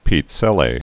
(pēt-sĕlā, pĭt-sĕl)